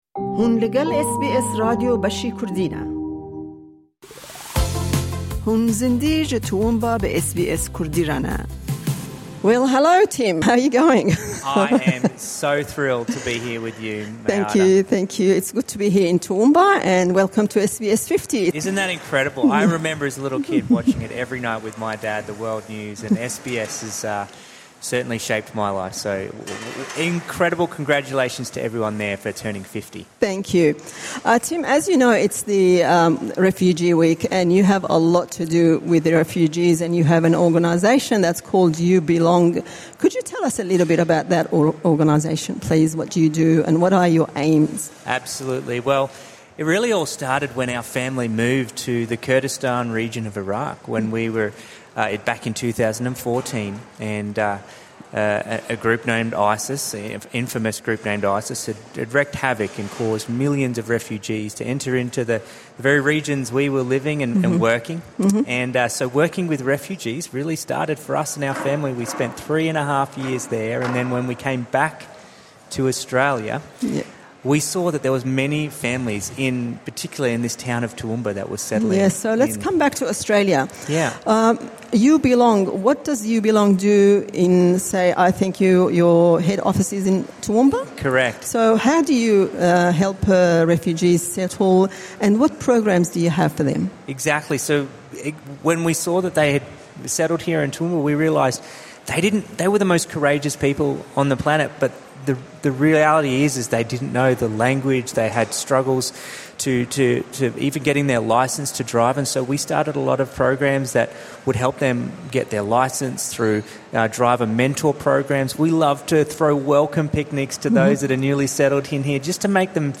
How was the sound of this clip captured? SBS travelled to regional Queensland for an outside broadcast (OB) in Toowoomba, a city that has welcomed numerous refugees over the past decade.